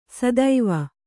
♪ sadaiva